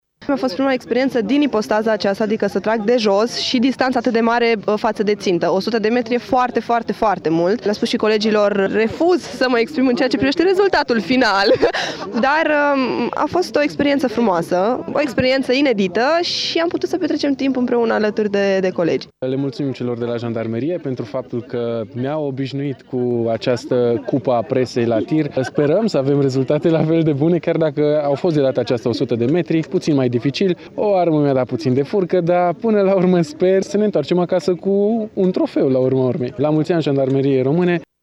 Cupa Presei la Tir, ediția 2016, s-a desfășurat astăzi la Poligonul de la Sângeorgiu de Mureș.
Presarii s-au prezentat în număr mare la evenimentul organizat de jandarmii mureșeni, arătându-se încântați de oportunitatea oferită: